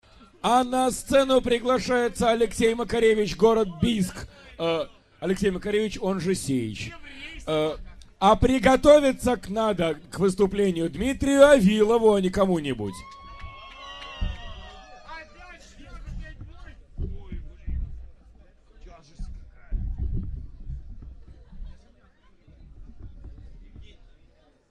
Белый слет 2003 г. Аудиоматериал